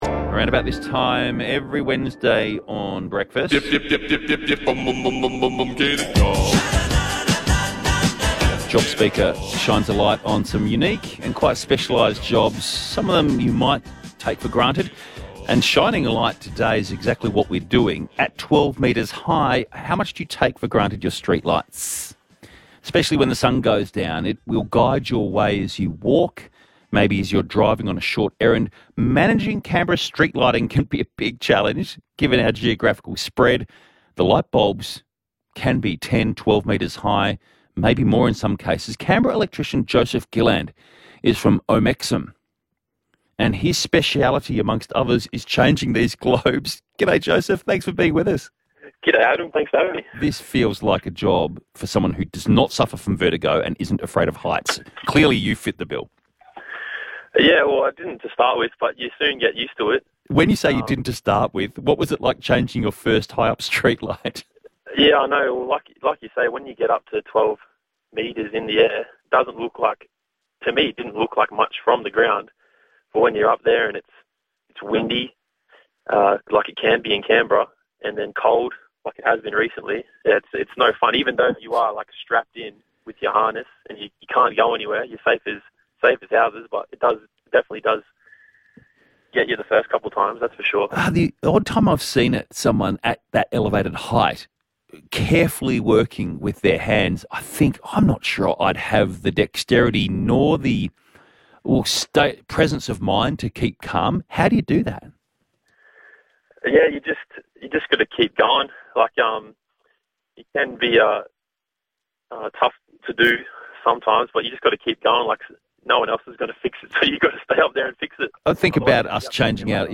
ABC radio interviewing